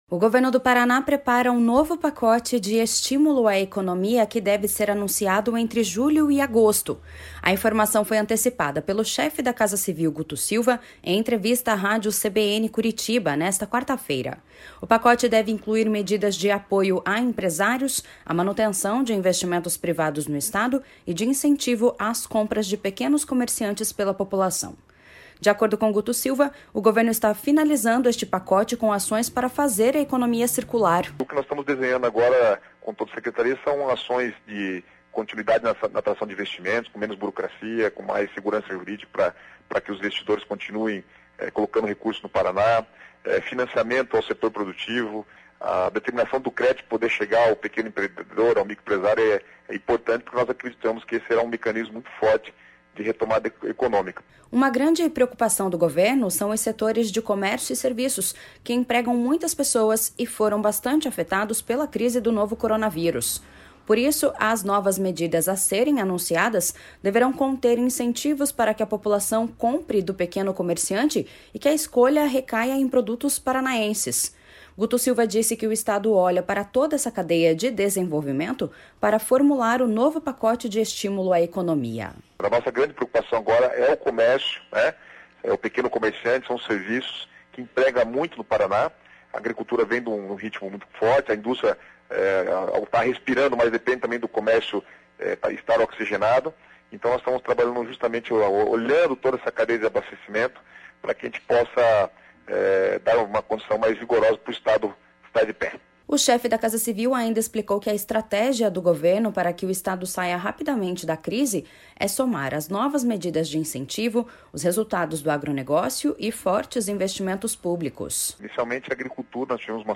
A informação foi antecipada pelo chefe da Casa Civil, Guto Silva, em entrevista à rádio CBN Curitiba nesta quarta-feira. O pacote deve incluir medidas de apoio a empresários, à manutenção de investimentos privados no Estado e de incentivo às compras de pequenos comerciantes pela população.